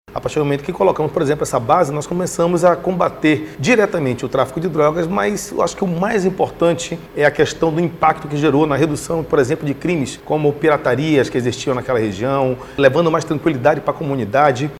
Na Base Arpão 1, que fica na região do município de Coari, com a ajuda de um cão farejador, os policiais conseguiram identificar um carregamento de entorpecente, avaliado em R$ 229 mil. O secretário de Segurança Pública do Amazonas, Coronel Vinicius Almeida, fala da importância da base arpão no combate ao tráfico de drogas.